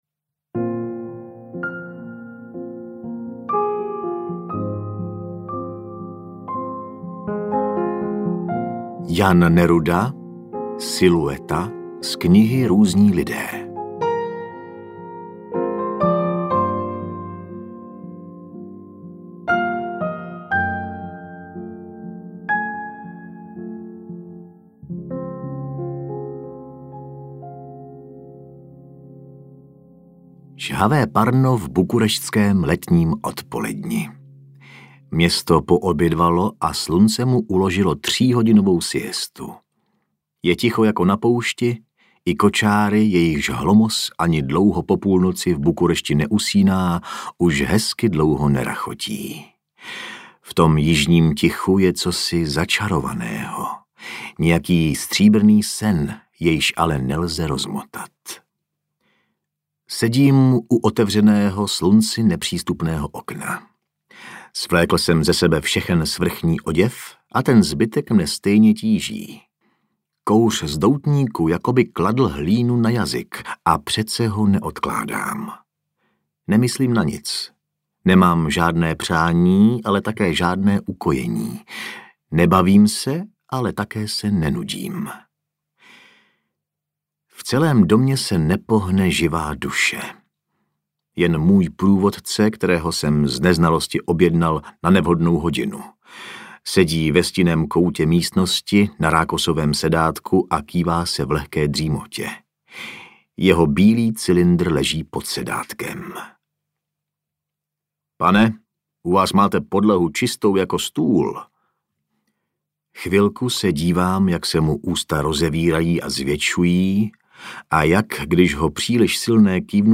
Audio kniha
Ukázka z knihy